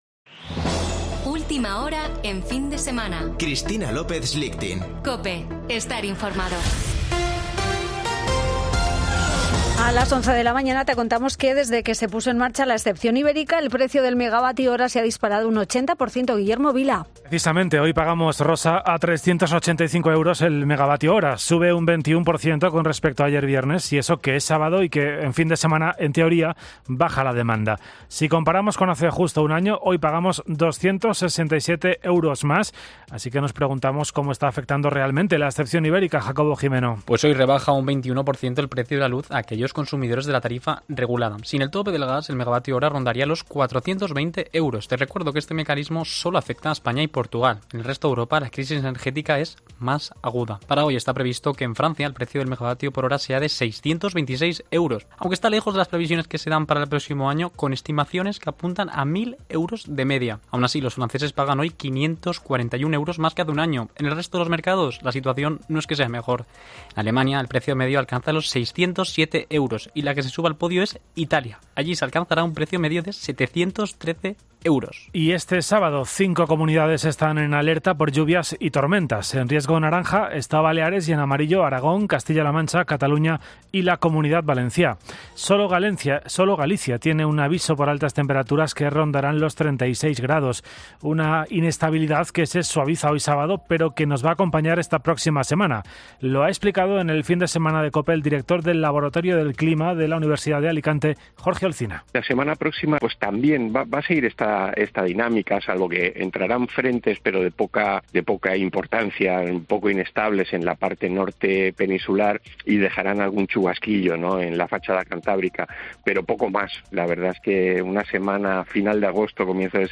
Boletín de noticias de COPE del 27 de agosto de 2022 a las 11.00 horas